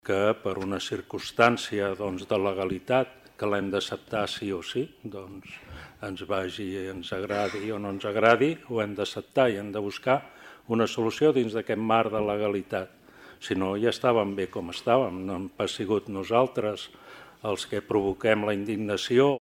L’alcalde de Platja d’Aro, Joan Giraut, reclama buscar solucions dins del marc de la llei.